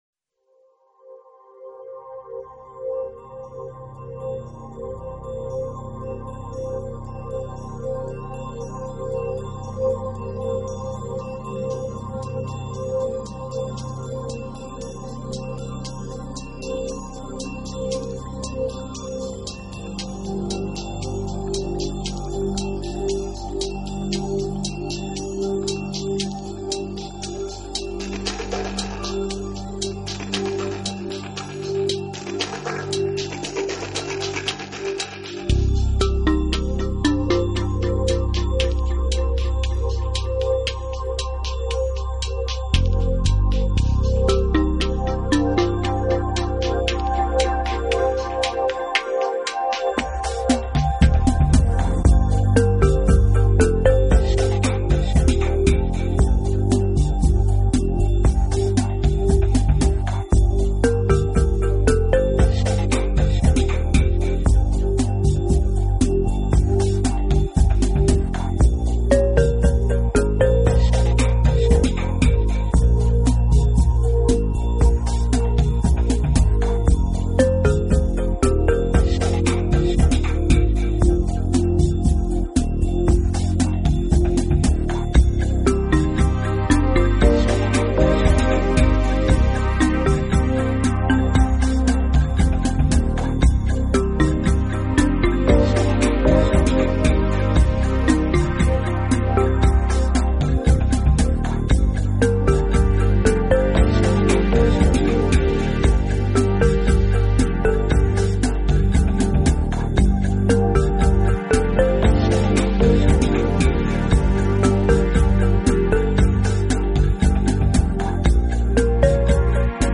风格: New Age
轻松而温馨